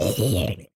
sounds / mob / zombie / death.mp3
death.mp3